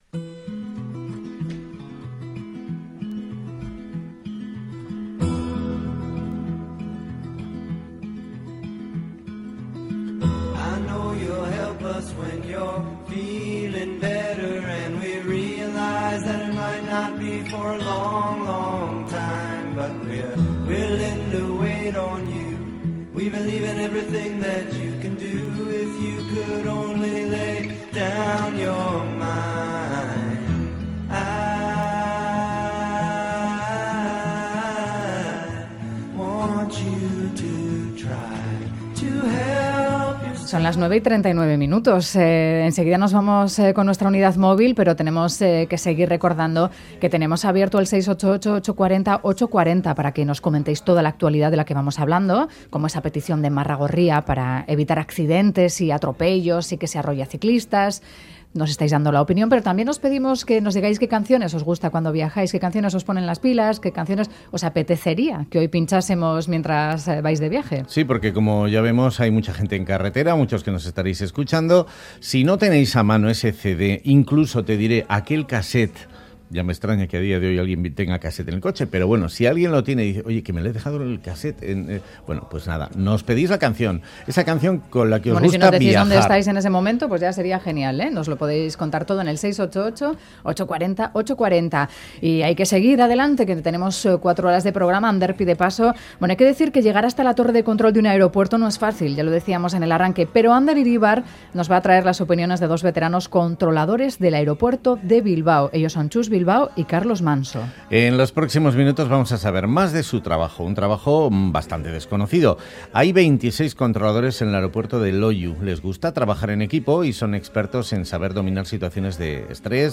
desde el fanal de la torre de control de Loiu.